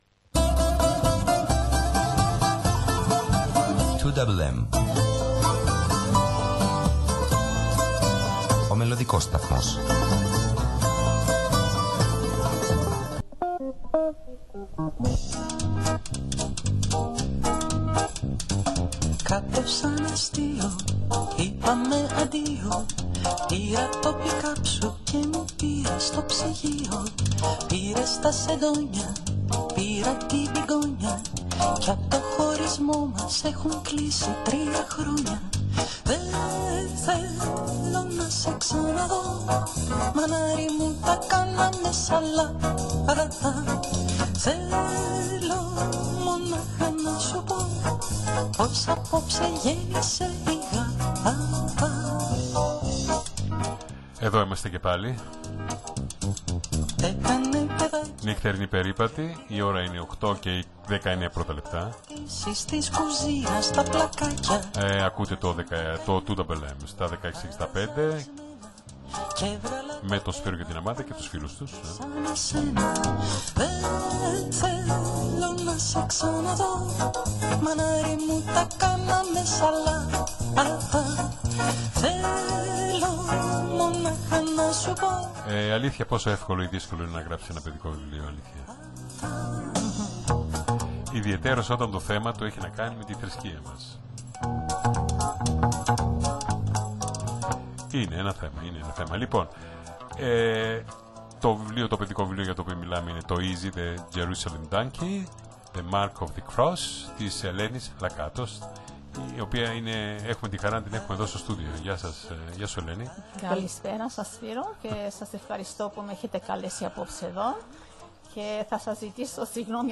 Στην συνέντευξη